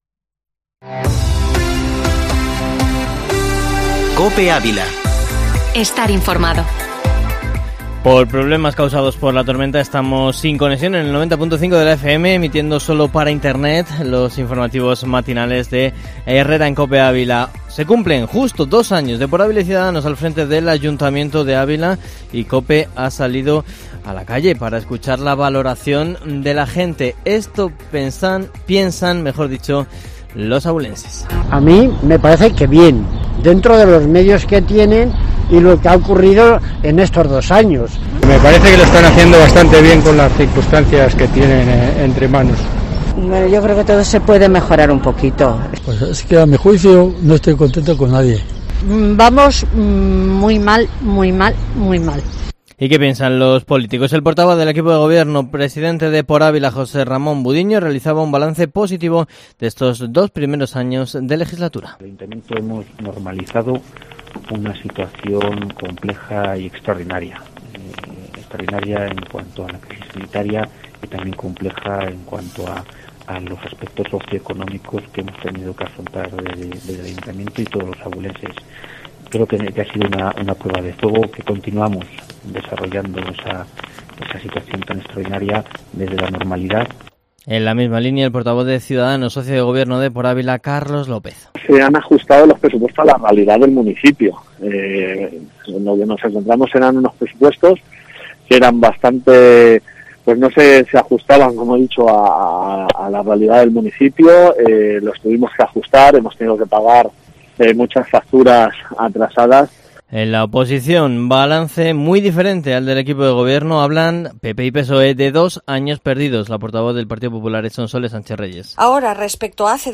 Informativo matinal Herrera en COPE Ávila 16-junio